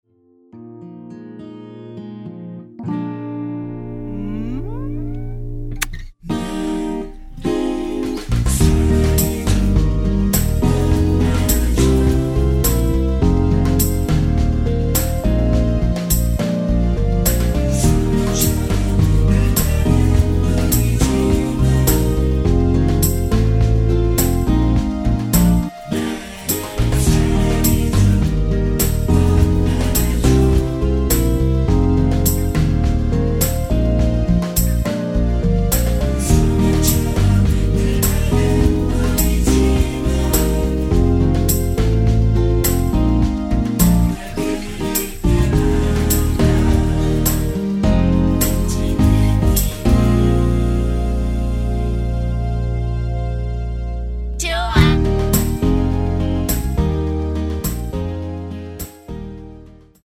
(-1)코러스 포함된 MR입니다.(미리듣기 참조) 발매일 2000.09
Eb
◈ 곡명 옆 (-1)은 반음 내림, (+1)은 반음 올림 입니다.
앞부분30초, 뒷부분30초씩 편집해서 올려 드리고 있습니다.